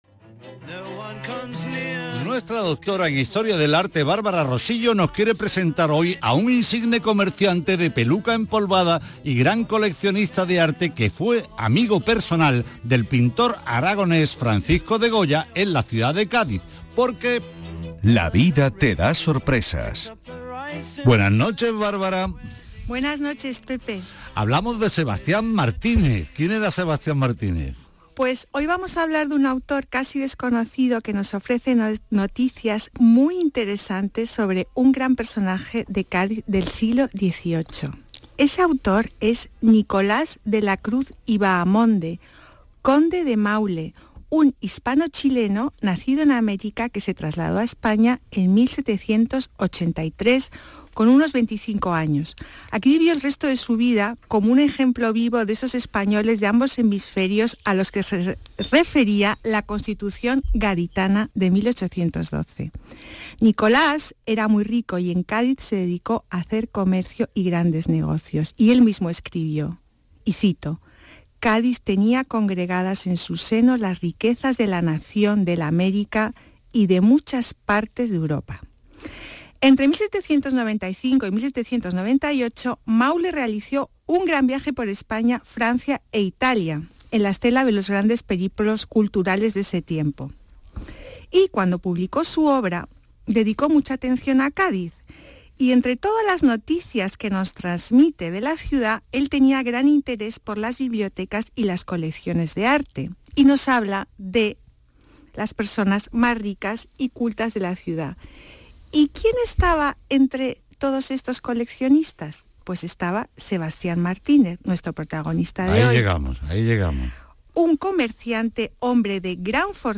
Aquí os dejo mi intervención en el programa de Radio Andalucía Información, «Patrimonio andaluz» del día 23/10/2022